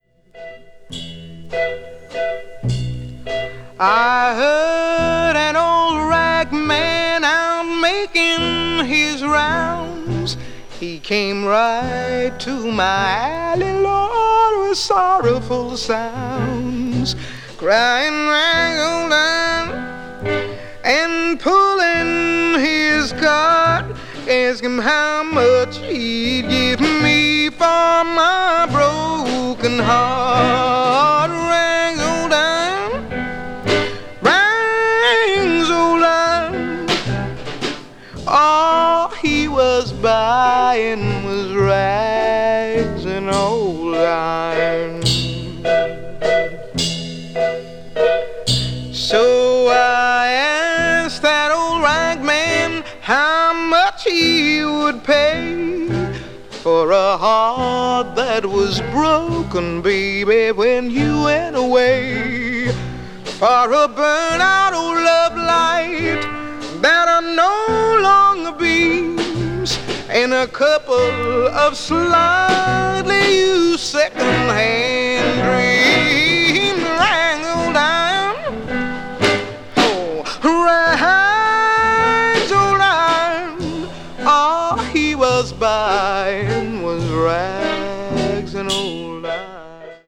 Mono
bles jazz   blues   jazz vocal   soul jazz